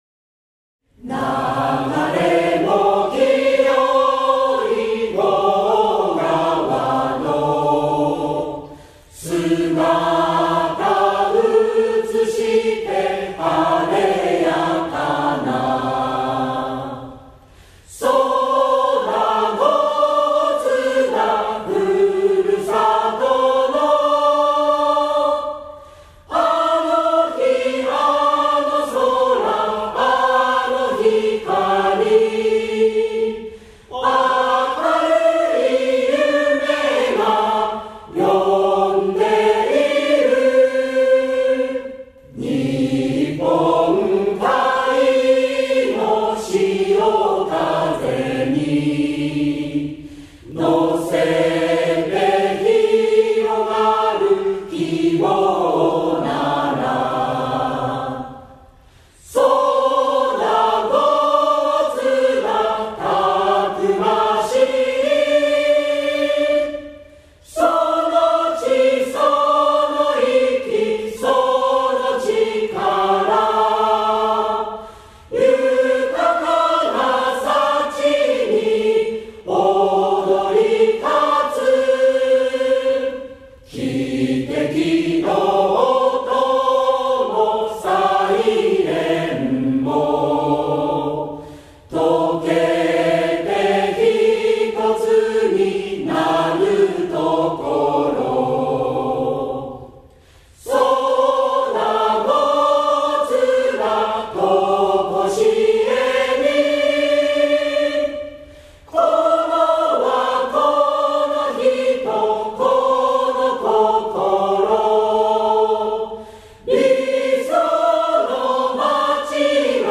江津市歌　音源（コーラスのみ）【歌：江津市民混声合唱団】 [その他のファイル：1.94MB]